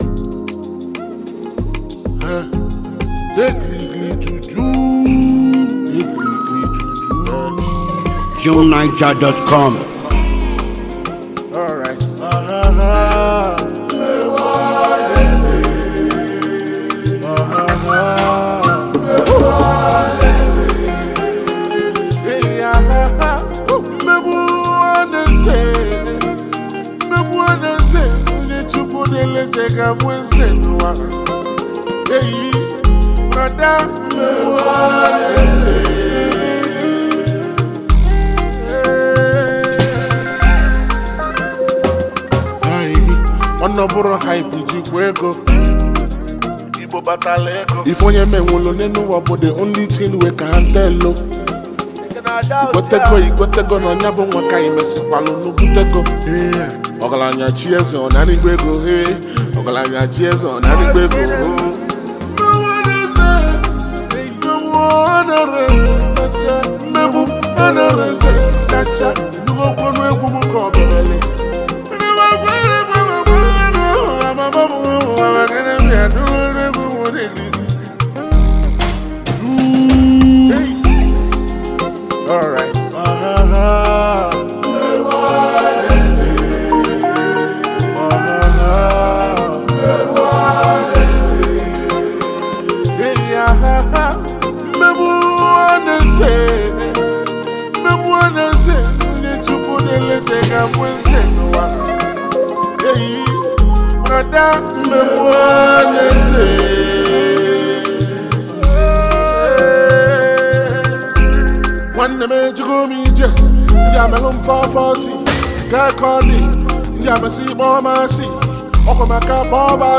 a well-known Nigerian highlife music artist